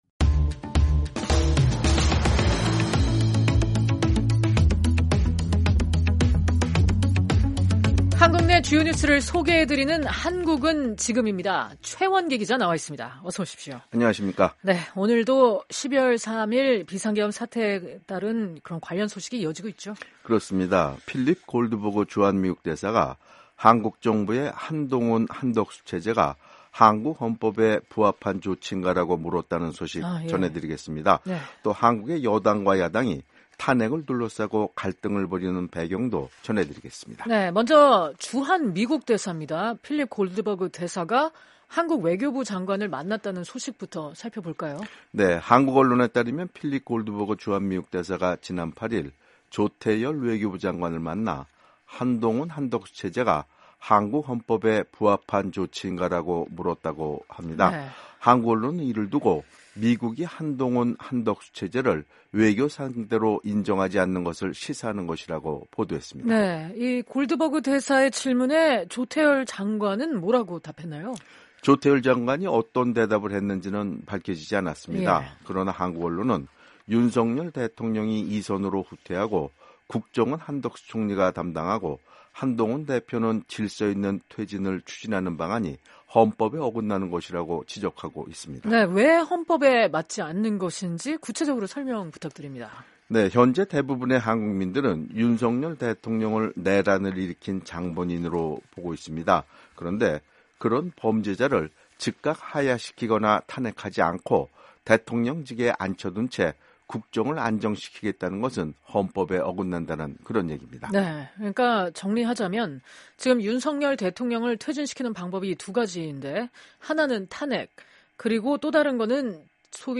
한국 내 주요 뉴스를 소개해 드리는 ‘한국은 지금’입니다.